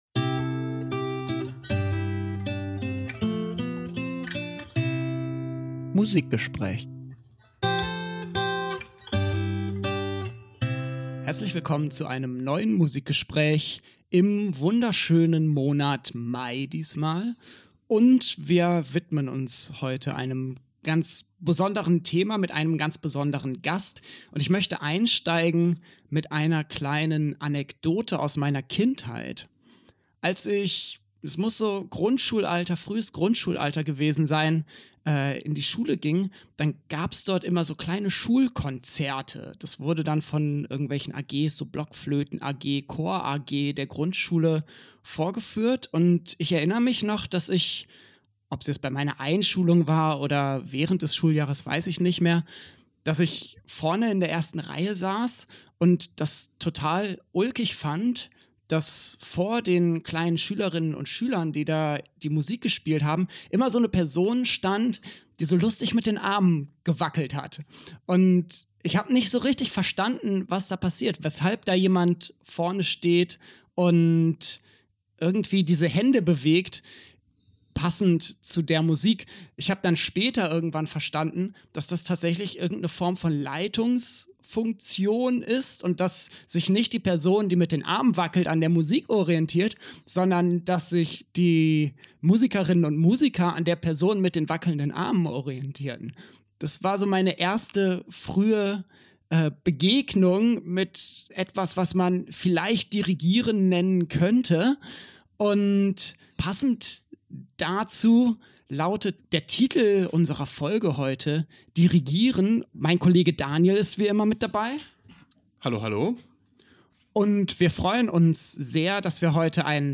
Was ist Dirigieren? Wie wird man Dirigentin? Wir sprechen mit der Dirigentin